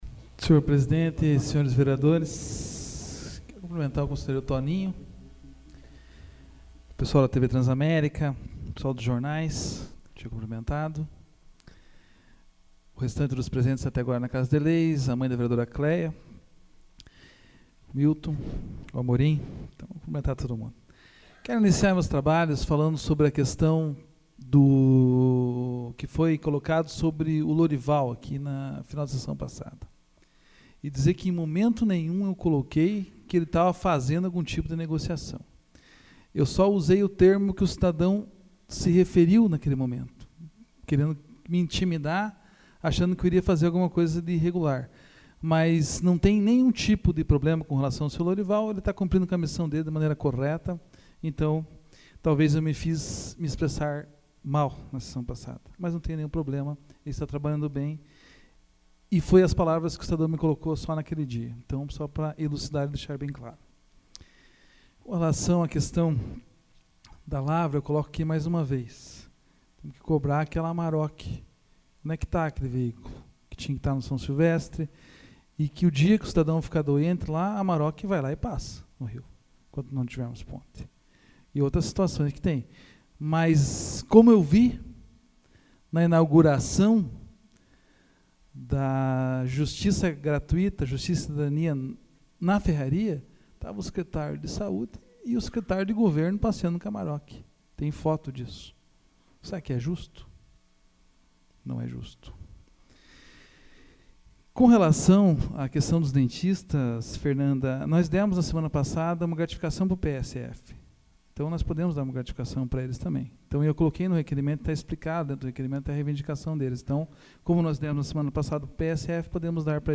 SAPL - Câmara Municipal de Campo Largo - PR
Explicações pessoais AVULSO 08/04/2014 João Marcos Cuba